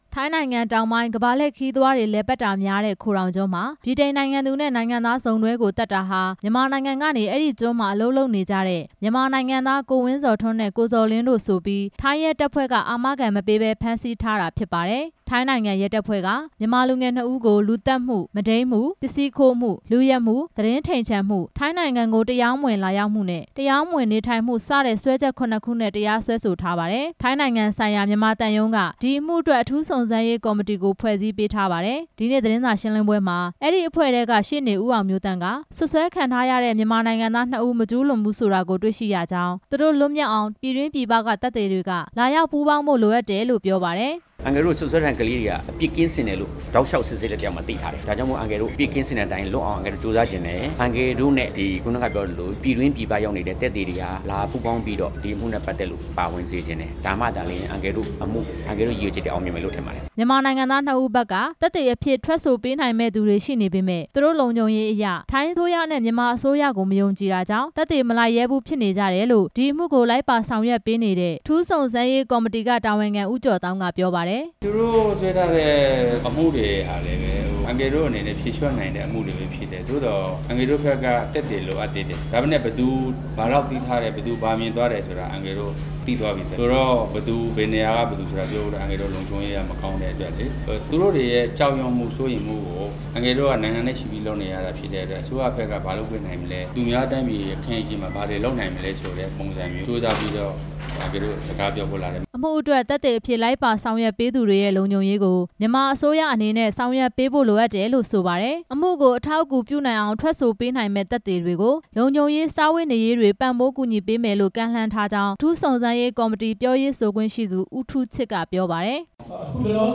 လိပ်ကျွန်းအမှု အထူးစုံစမ်းရေးကော်မတီ သတင်းစာရှင်းလင်းပွဲ